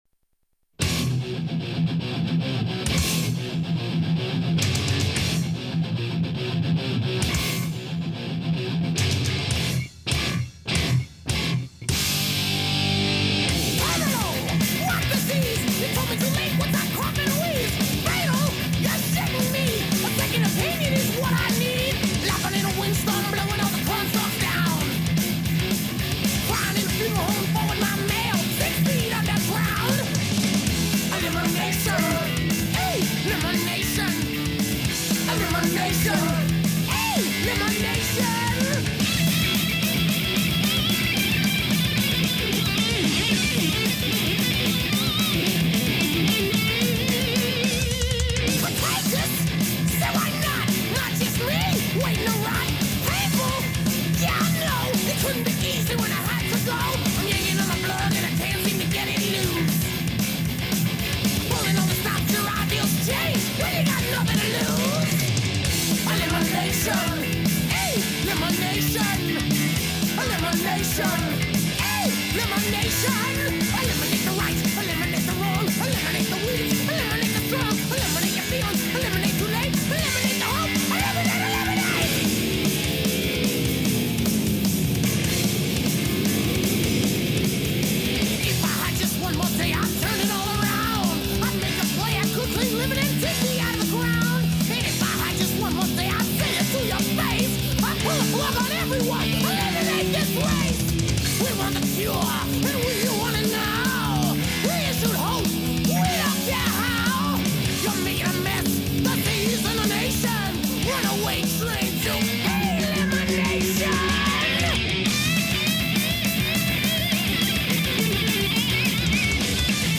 1989 Genre: Thrash Metal